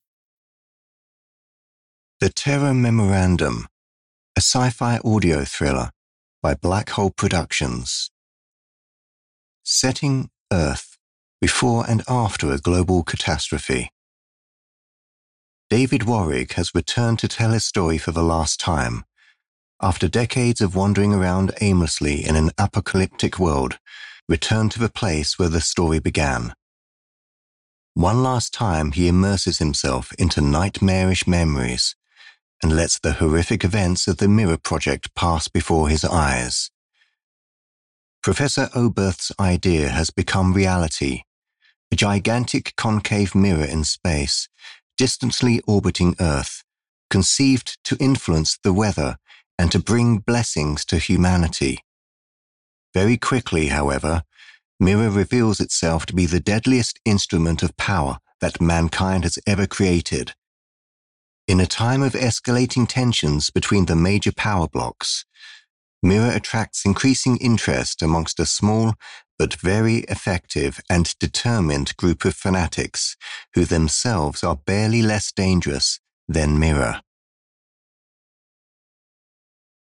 Adult (30-50) | Older Sound (50+)
Movie Trailer Voice Overs